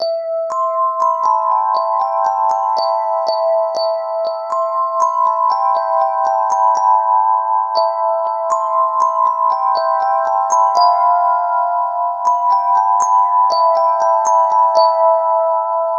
Rez Bell.wav